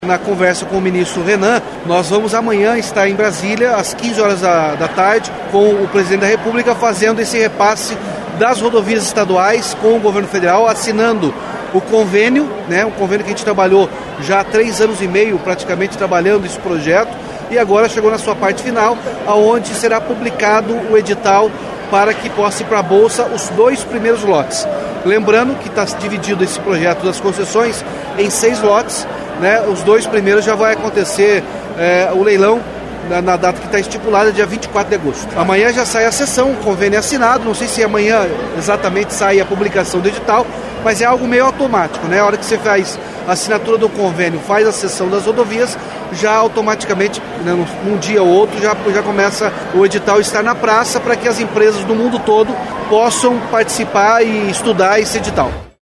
Player Ouça Ratinho Júnior, governador do Paraná